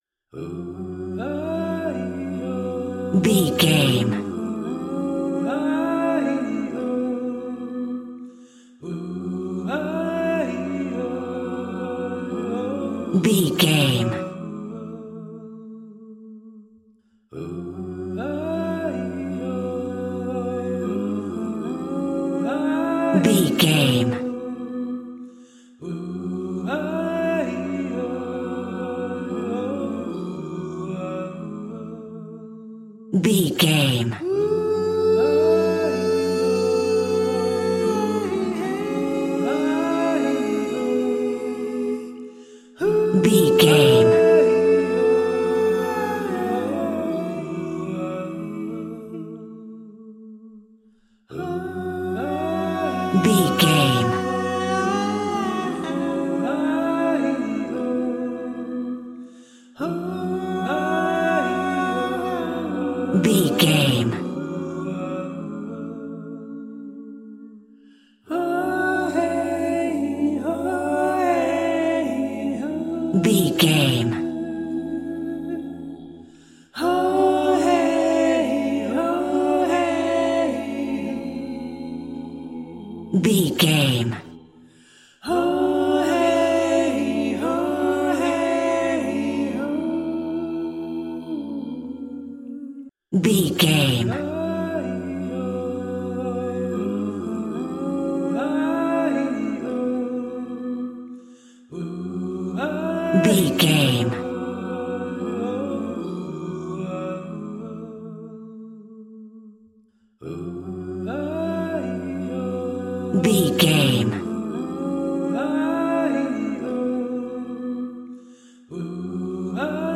Aeolian/Minor
G♭
groovy
vocal